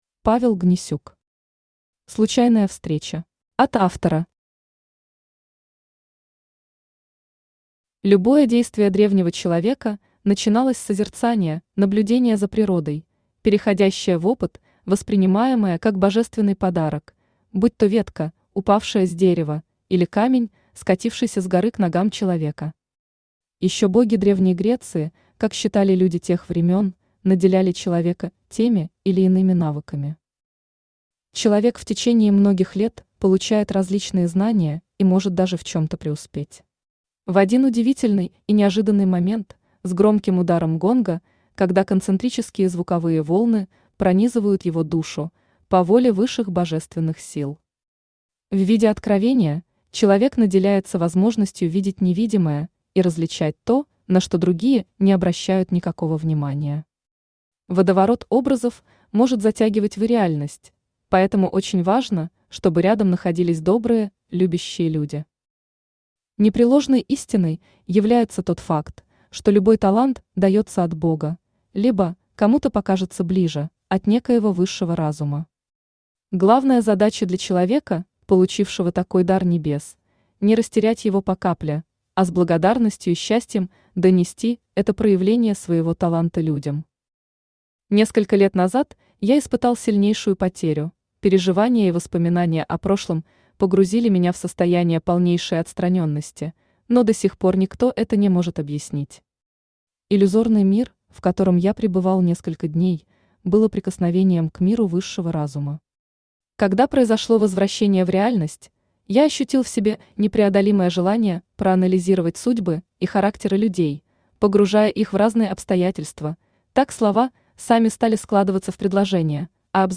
Аудиокнига Случайная встреча | Библиотека аудиокниг
Aудиокнига Случайная встреча Автор Павел Борисович Гнесюк Читает аудиокнигу Авточтец ЛитРес.